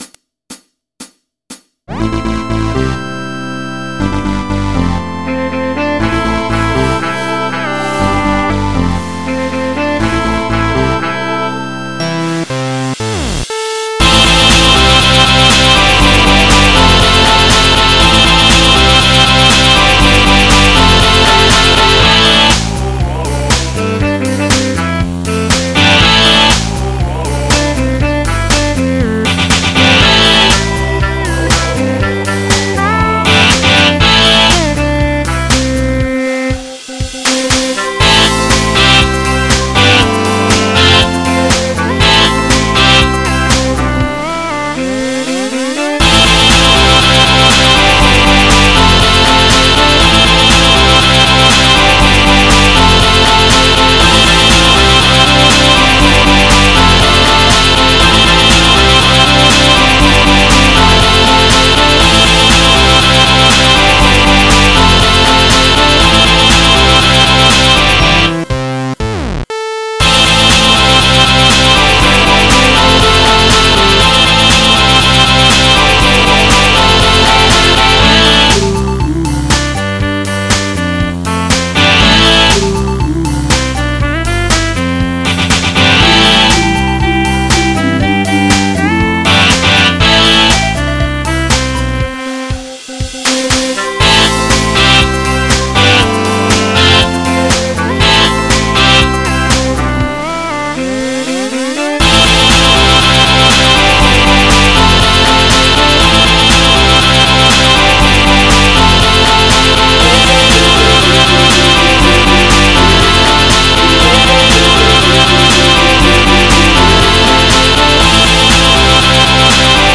MIDI 252.75 KB MP3 (Converted)